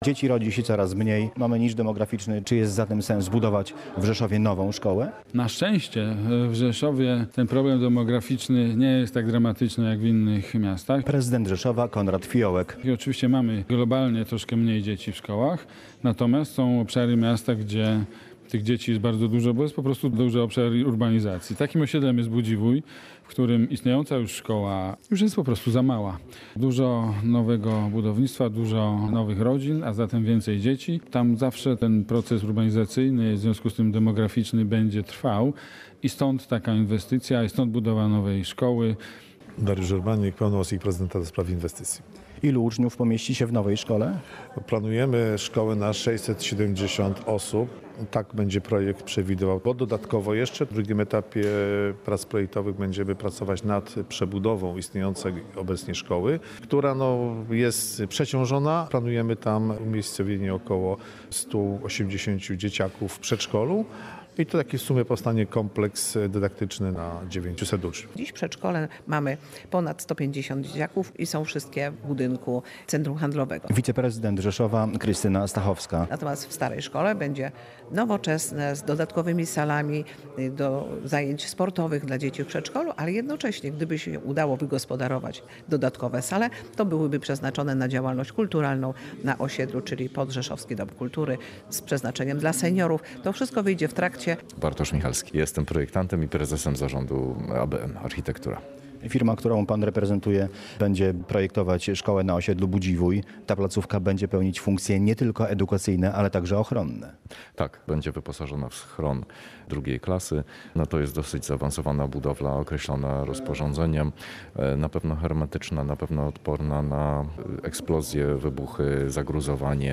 Będzie też pełnić funkcję schronu • Relacje reporterskie • Polskie Radio Rzeszów